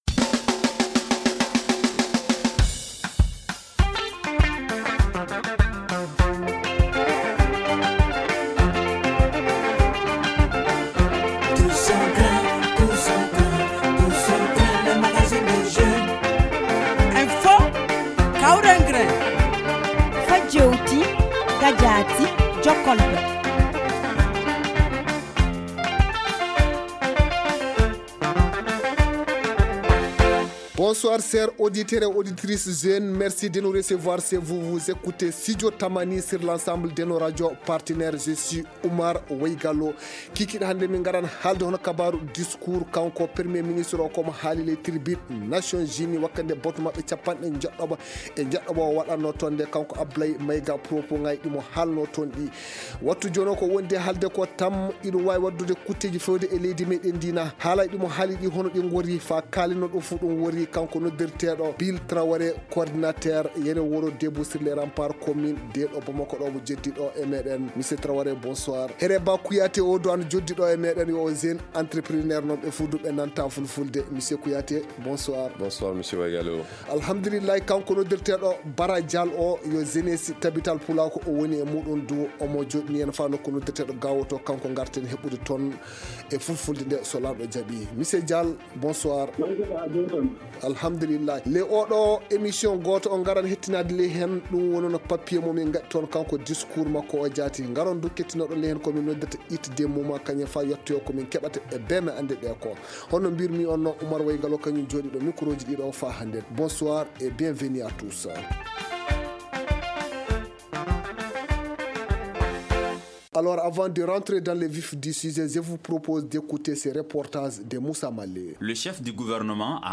Le tous au grin pose le débat nos invités sont :